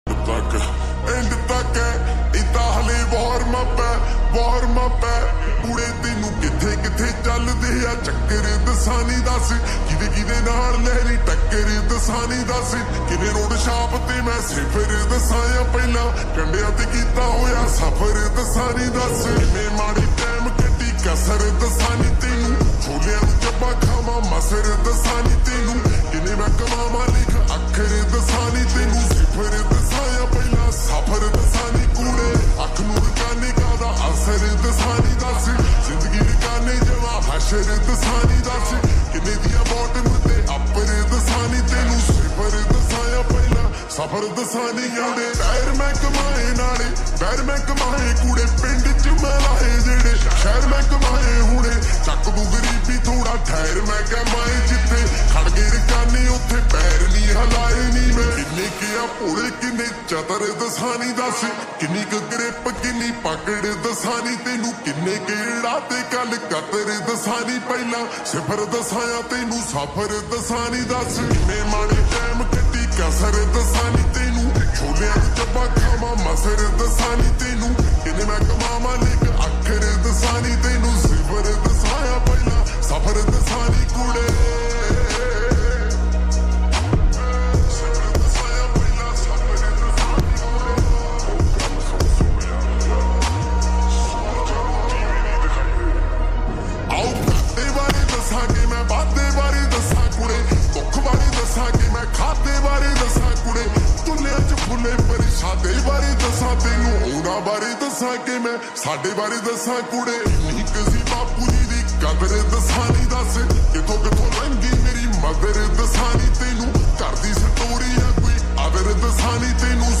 SLOWED AND REVERB FULL SONG PUNJABI